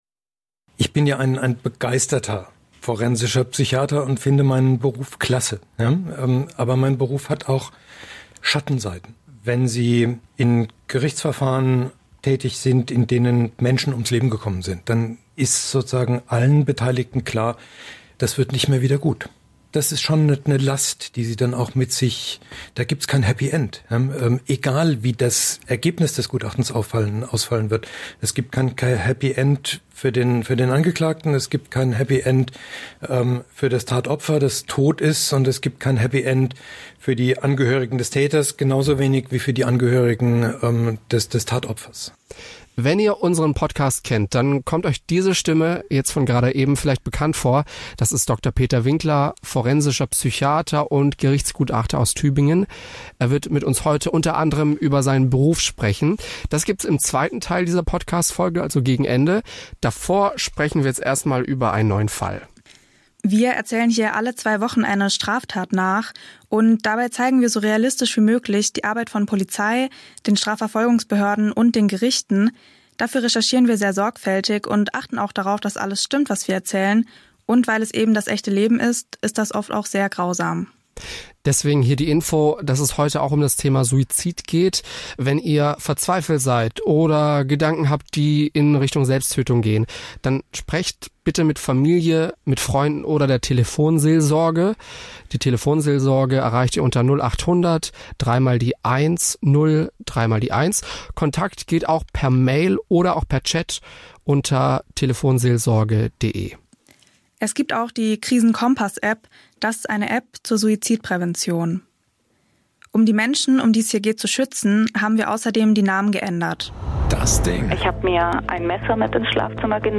(49:04) Interview